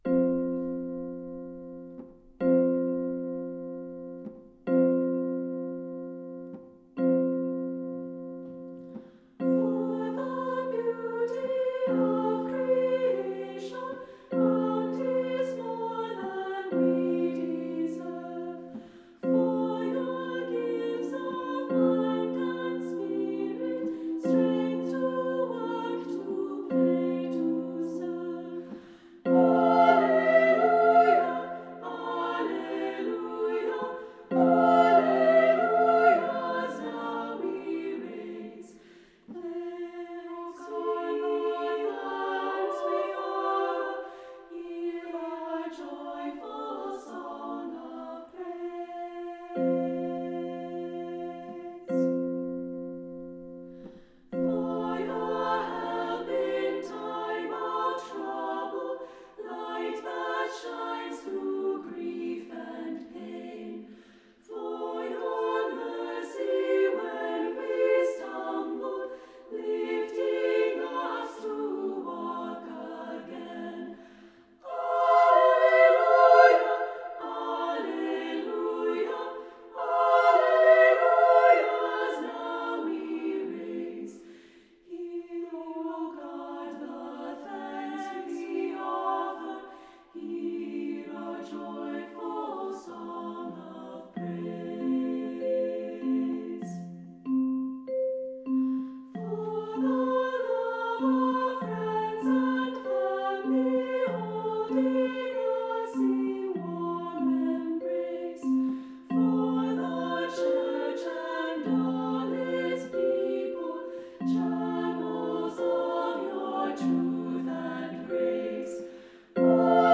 Voicing: Unison; Two-part equal; Three-part equal